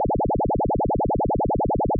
falling_rooms/Sine Wave Modulated.wav at master
Sine Wave Modulated.wav